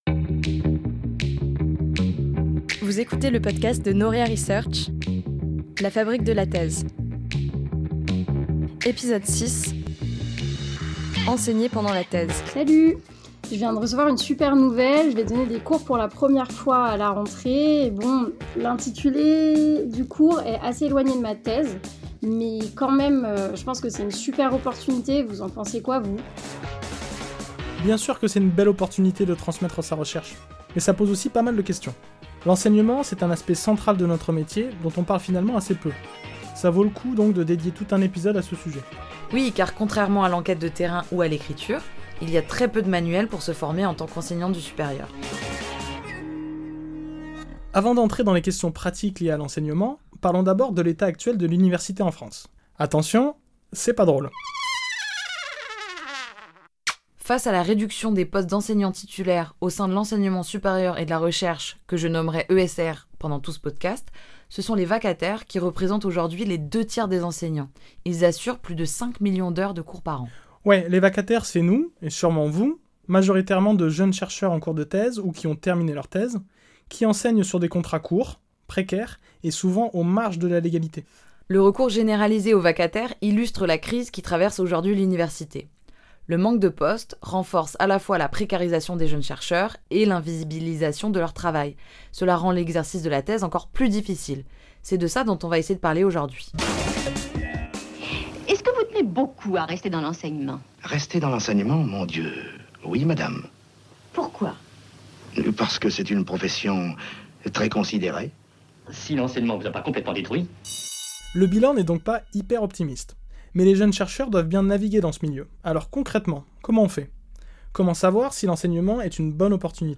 Entretien
À travers les témoignages de doctorant·es et de jeunes chercheur·ses, cet épisode de La Fabrique de la thèse explore comment identifier et décrocher des missions d’enseignement au sein de l’ESR, gérer les enjeux financiers et construire sa pédagogie.